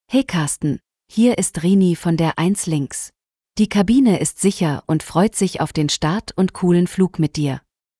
CallCabinSecureTakeoff.ogg